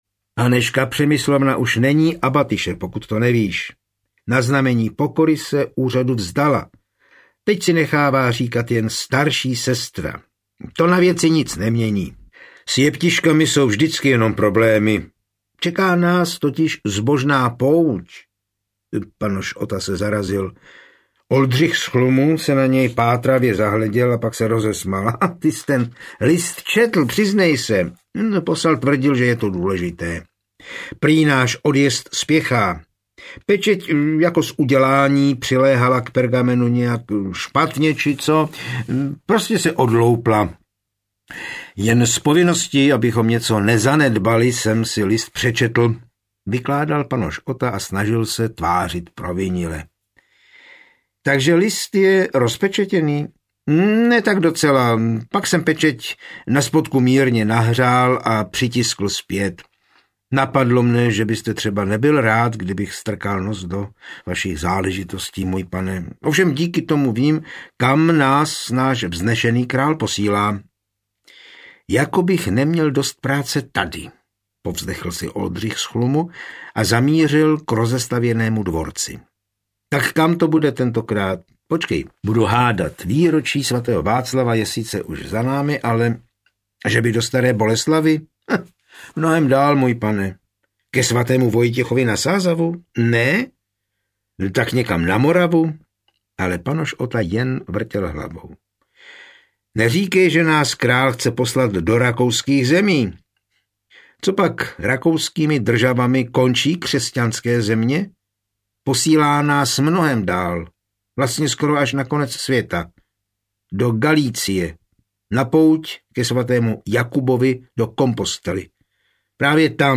Tajemství abatyše z Assisi audiokniha
Ukázka z knihy